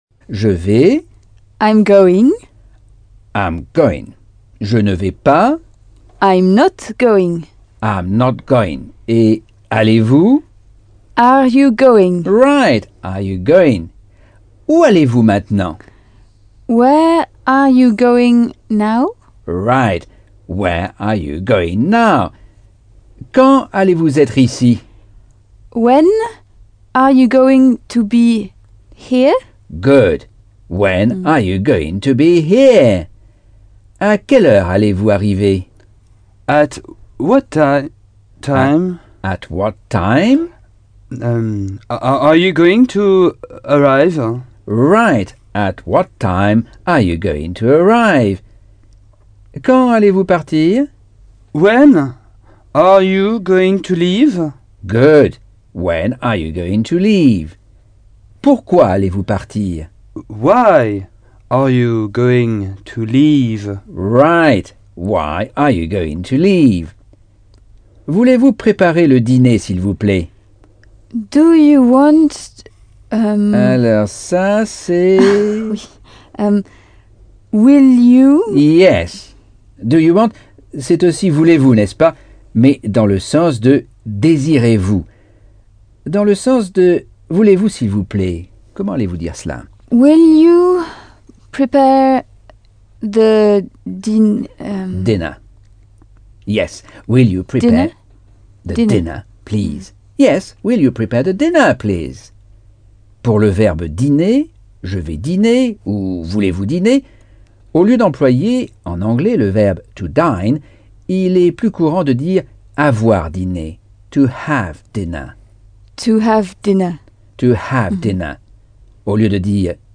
Leçon 8 - Cours audio Anglais par Michel Thomas